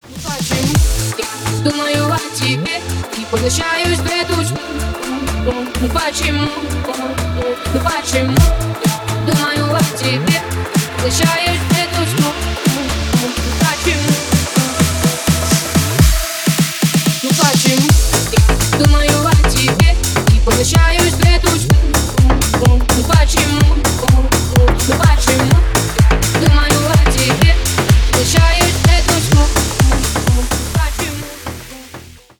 Ремикс # Поп Музыка
ритмичные
клубные